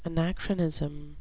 anachronism (uh-NAK-ruh-niz-uhm) noun
Pronunciation: